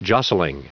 Prononciation du mot jostling en anglais (fichier audio)
Prononciation du mot : jostling